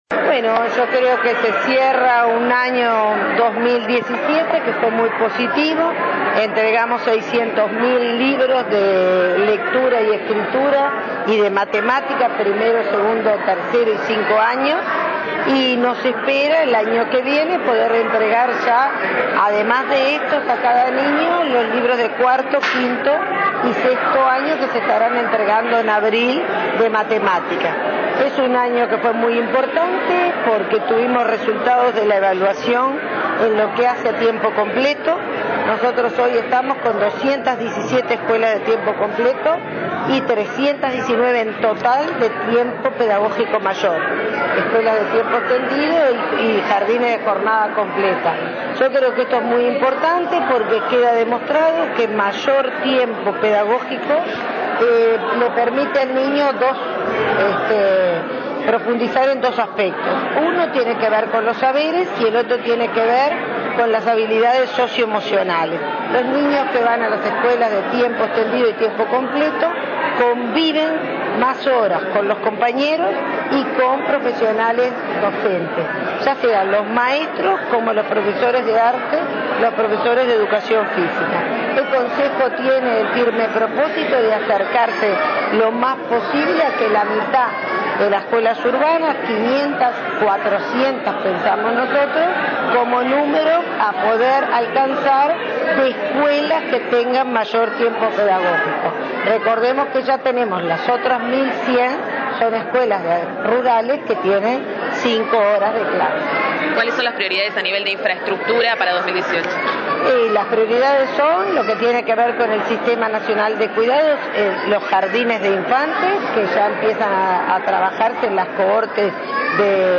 La titular de Primaria, Irupé Buzzetti, informó que en 2017 se entregaron 600 mil cuadernos de apoyo para niños de inicial y primaria y que en 2018 la cifra se ampliará cubriendo a los escolares entre nivel 5 y 6° en lectura, escritura y matemáticas. Confirmó que avanzarán en la construcción de jardines de infantes para atender demanda del Sistema de Cuidados y ampliarán modalidad de centros educativos asociados de 6 a 18.